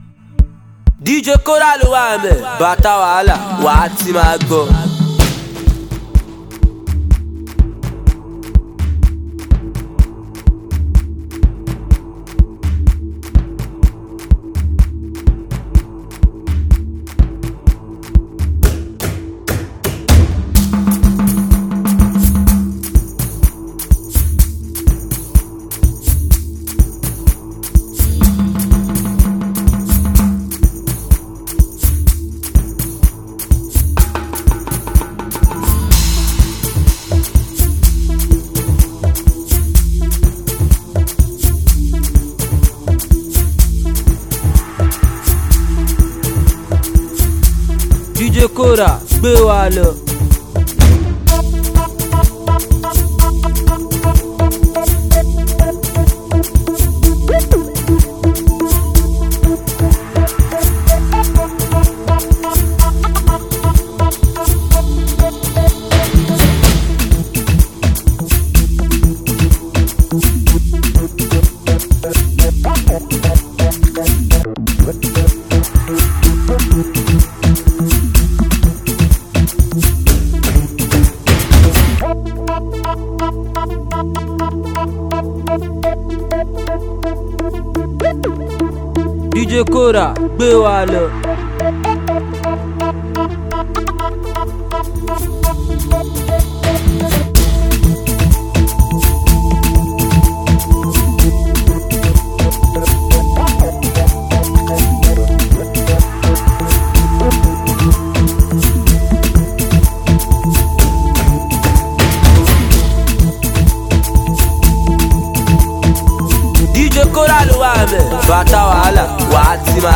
gbedu